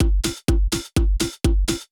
DRUMLOOP208_HOUSE_125_X_SC2.wav